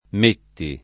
[ m % tti ]